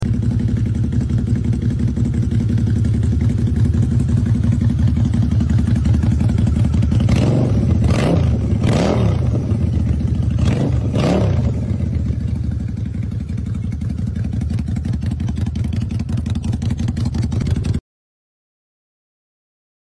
Check Sound VROAD